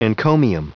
Prononciation du mot encomium en anglais (fichier audio)
Prononciation du mot : encomium